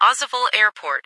- New ATIS Sound files created with Google TTS en-US-Studio-O